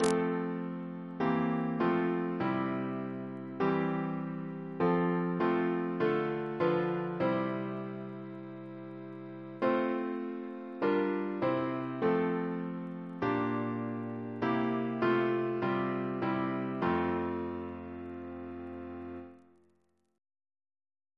Double chant in F Composer: Edward John Hopkins (1818-1901), Organist of the Temple Church Reference psalters: ACB: 220; ACP: 46; CWP: 80; H1940: 636; H1982: S191; OCB: 196; PP/SNCB: 151; RSCM: 74